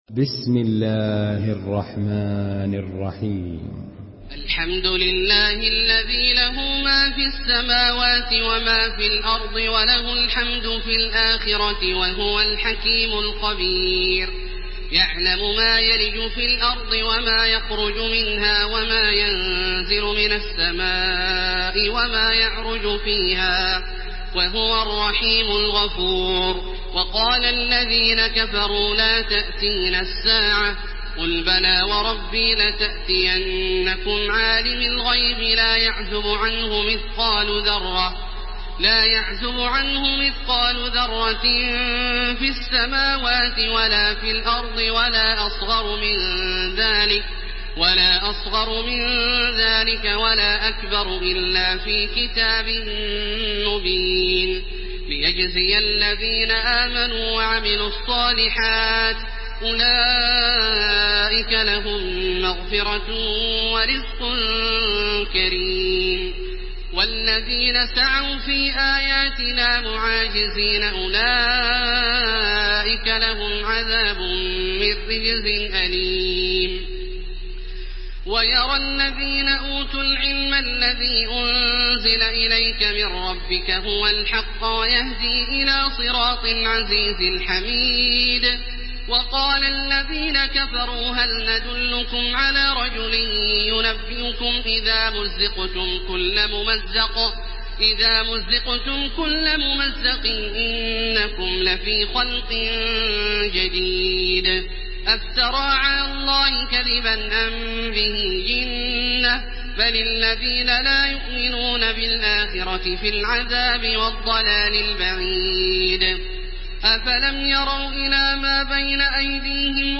تحميل سورة سبأ بصوت تراويح الحرم المكي 1430
مرتل